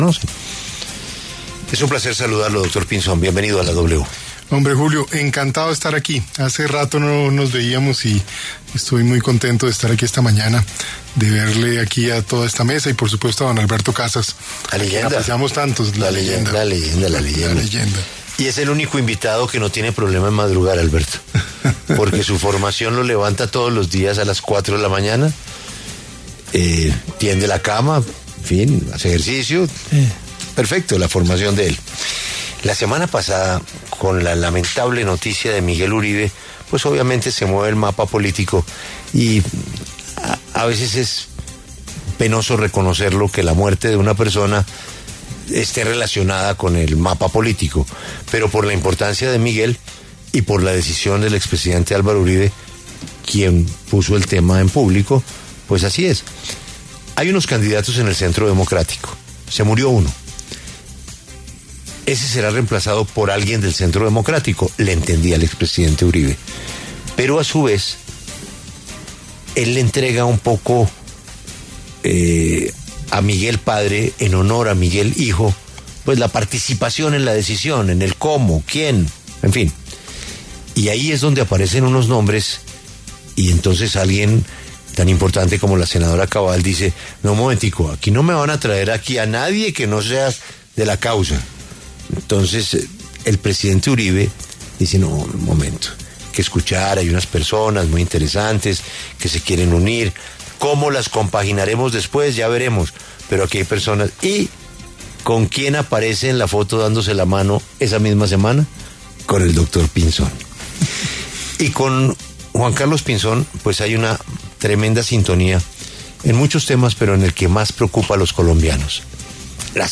El precandidato presidencial y exministro, Juan Carlos Pinzón, conversó con La W sobre sus iniciativas, su pasado político y sus posturas de cara a las elecciones del 2026.